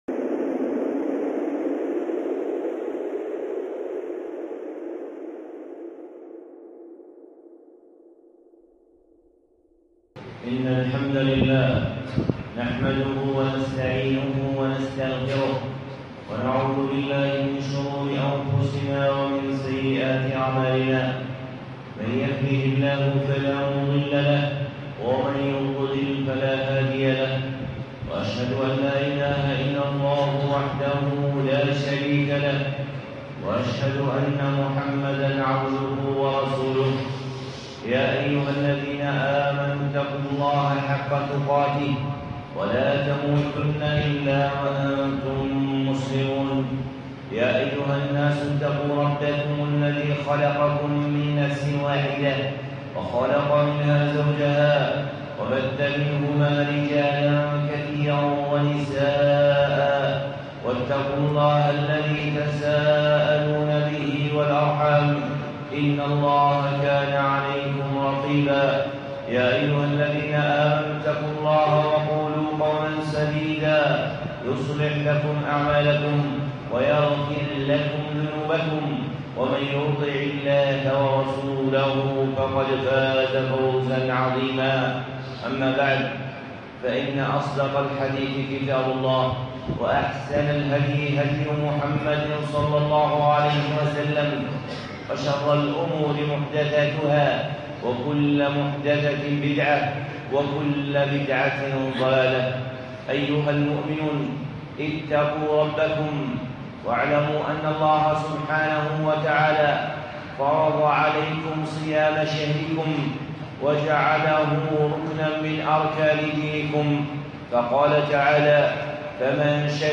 خطبة (الإعلام بمقصود الصيام)